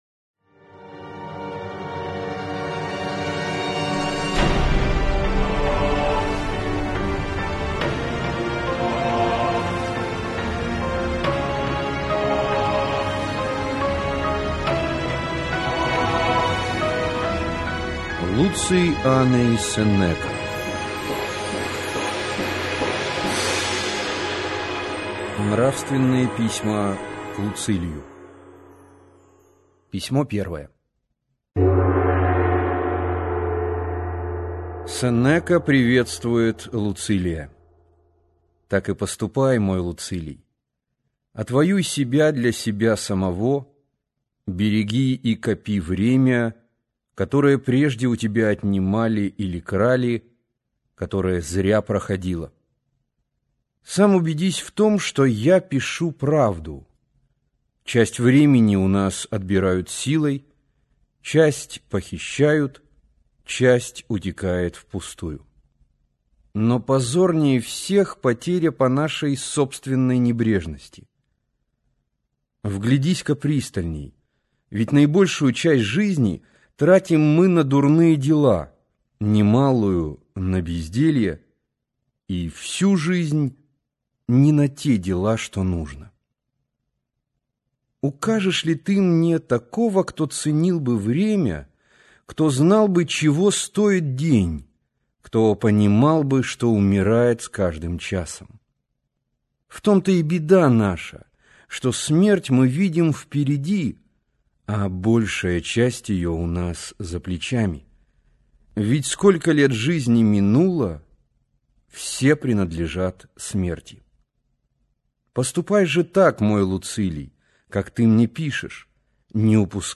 Аудиокнига Нравственные письма к Луцилию | Библиотека аудиокниг